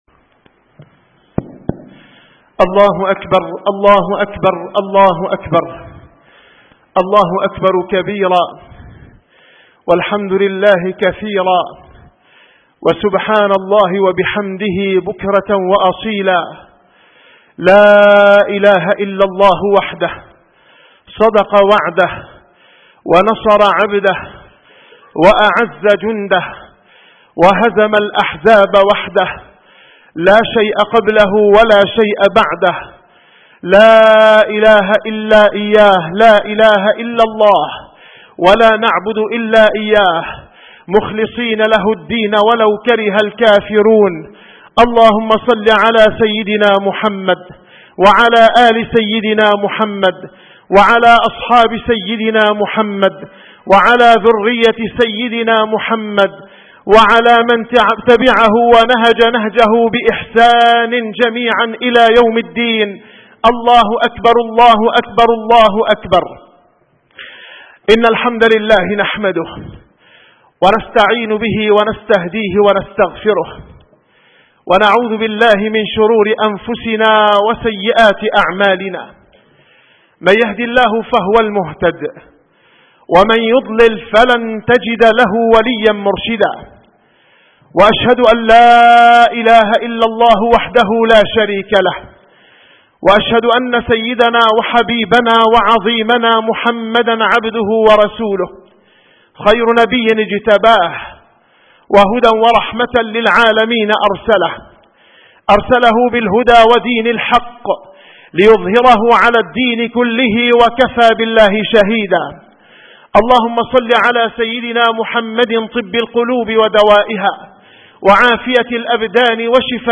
- الخطب - خطبة العيد